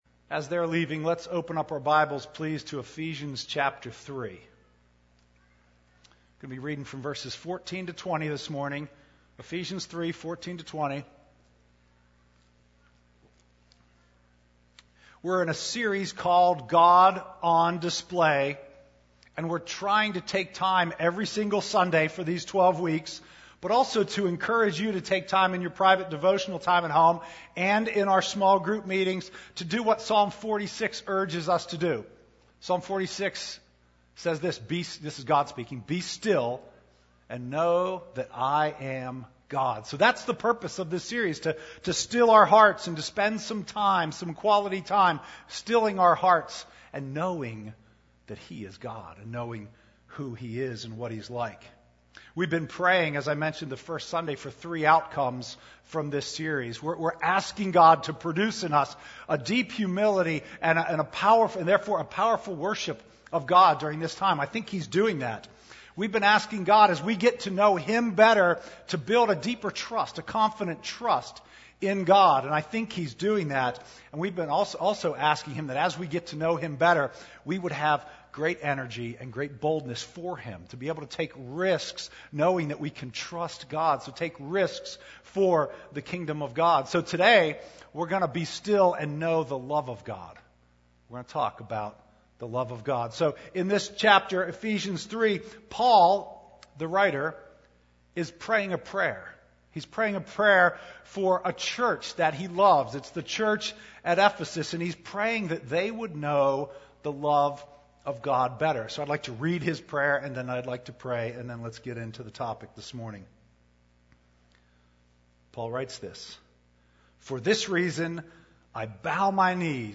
Download the weekly Bible Study that goes with this sermon.